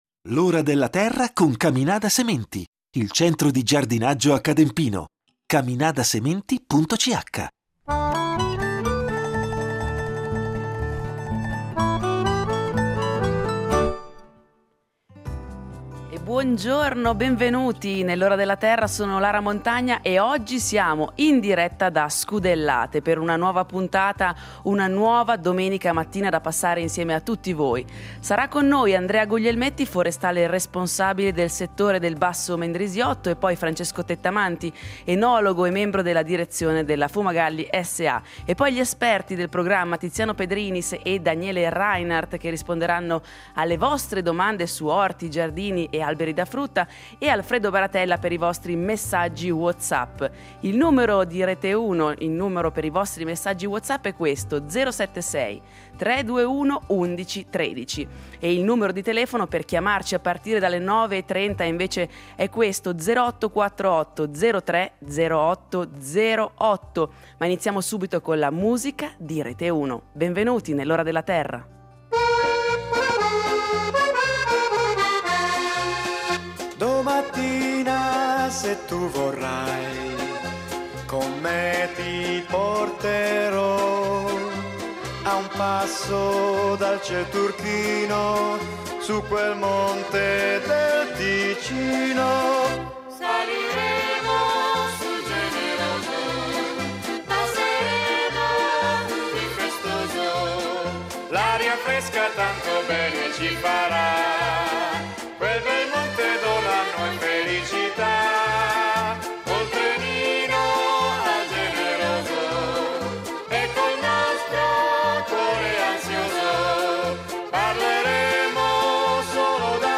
In diretta da Scudellate , vi parleremo dei boschi della valle di Muggio